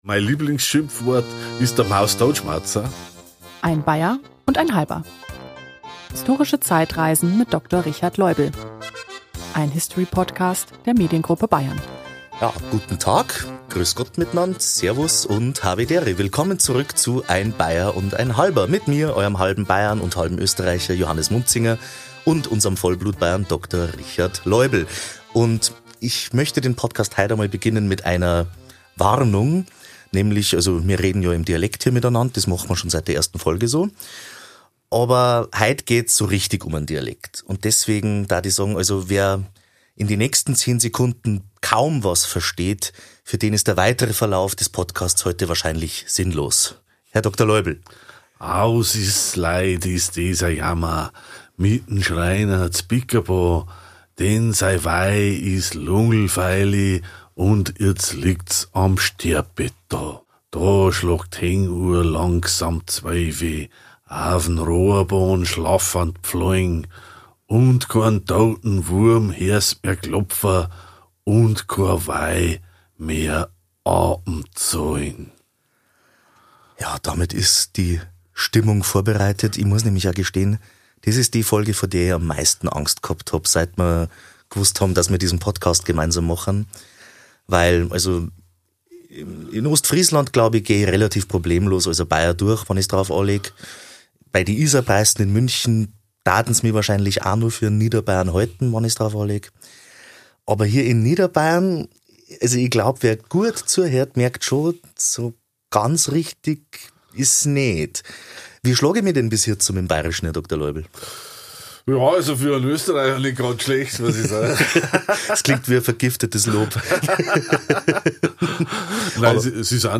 Er ist halber Bayern, halber Österreicher, und in seiner Jugend im Grenzland ist er dreisprachig aufgewachsen: Hochdeutsch, Bairisch, Oberösterreichisch.